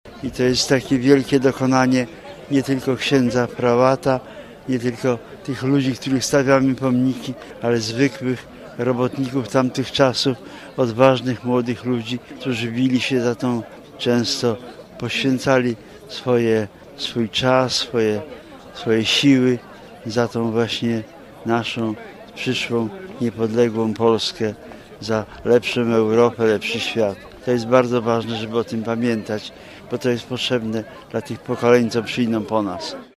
To ważne by pamiętać o czasach Solidarności i ludziach, którzy wówczas zmieniali Polskę – mówił nam marszałek senior Kornel Morawiecki, który wziął udział w dzisiejszych uroczystościach